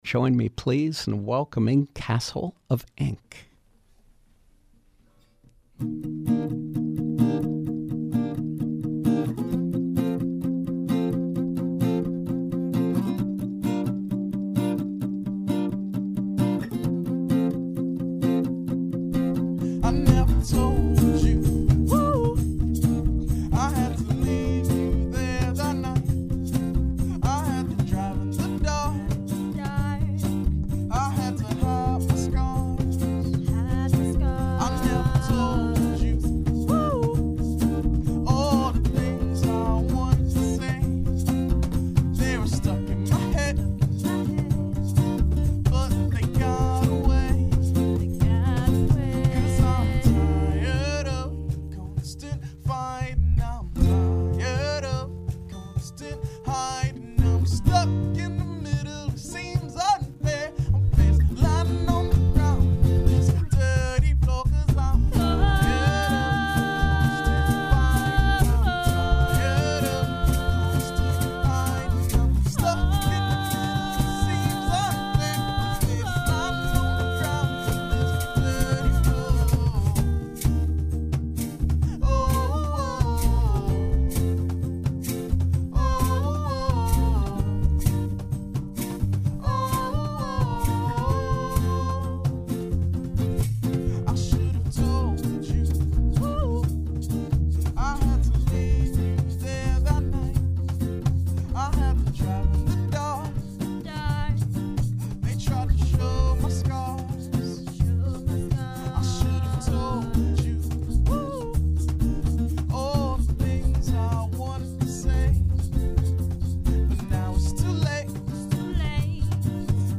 Live music